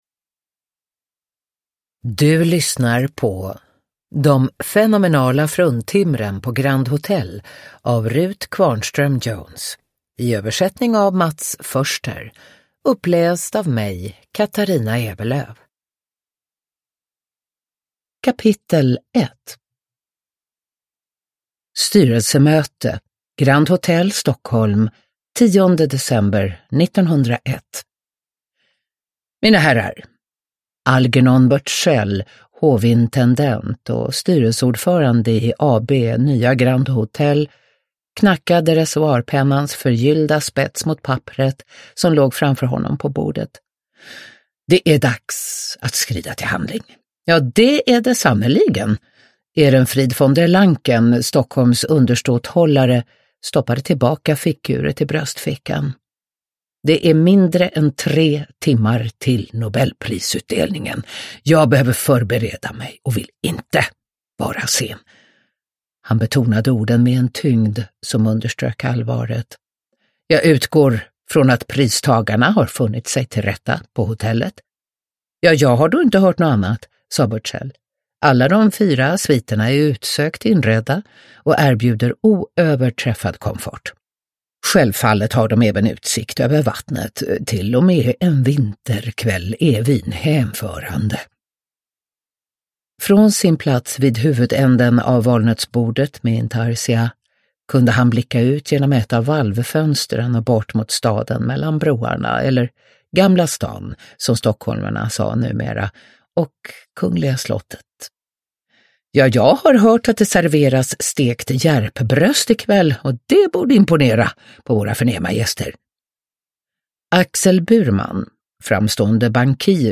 De fenomenala fruntimren på Grand Hôtel – Ljudbok – Laddas ner
Uppläsare: Katarina Ewerlöf